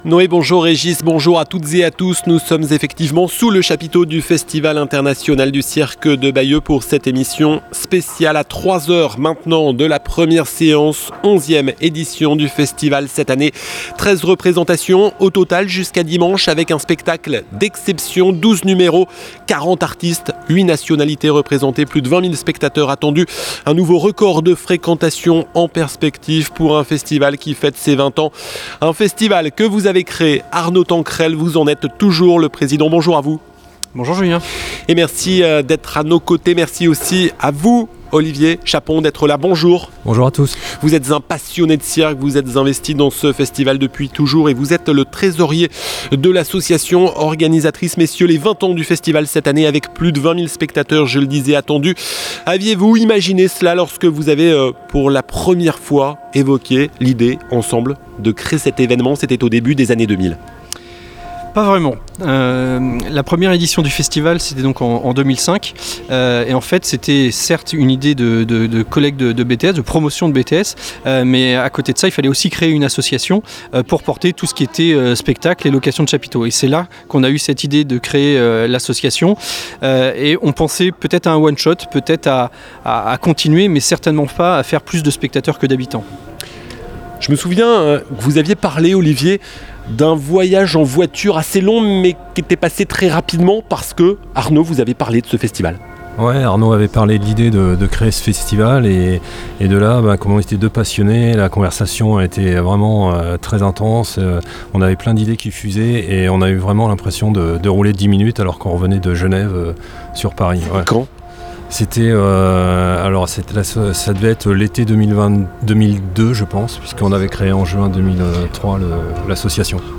RadioVFM au Festival Internation du Cirque de Bayeux